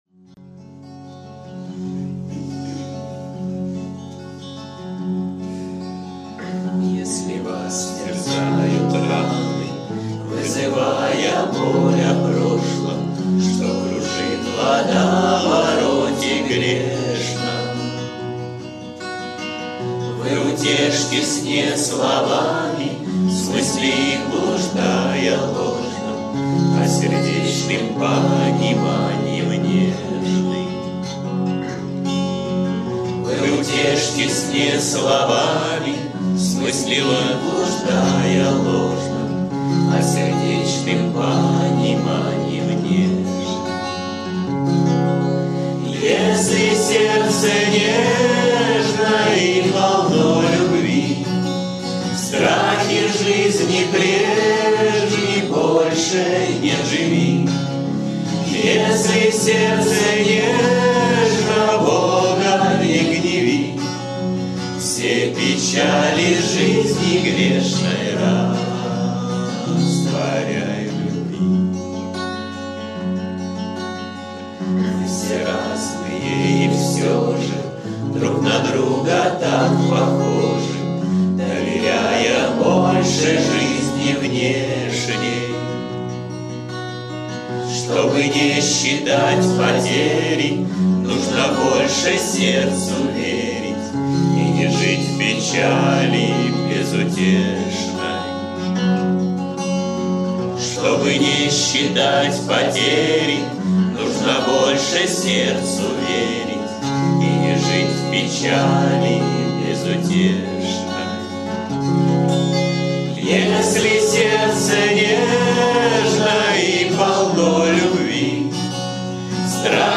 кавер-версия
Песни у костра